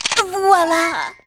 Worms speechbanks
COLLECT.wav